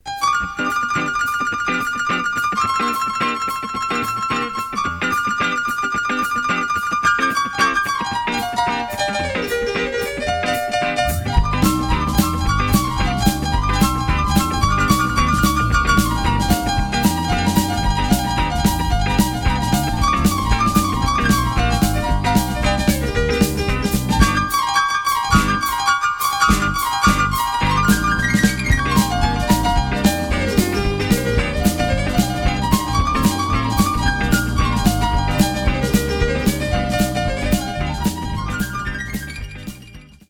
screaming piano and hammond solos
accompianment from a backing band